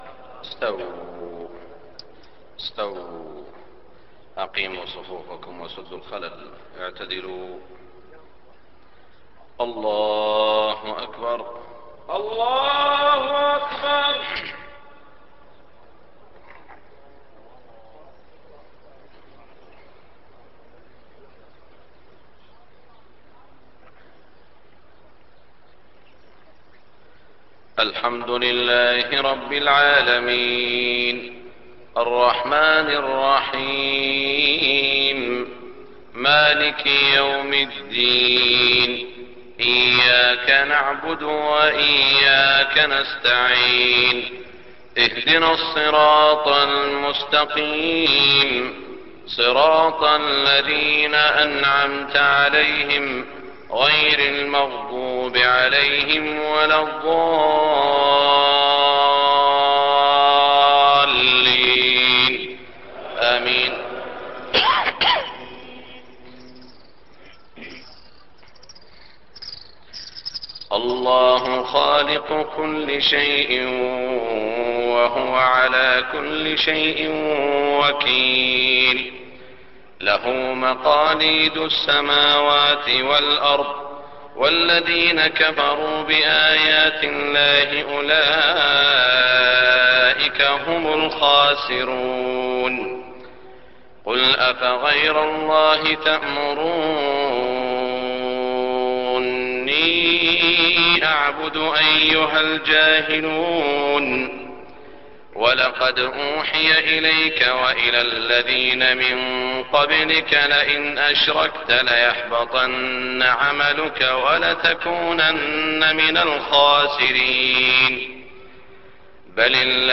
صلاة الفجر 7-9-1423هـ من سورة الزمر > 1423 🕋 > الفروض - تلاوات الحرمين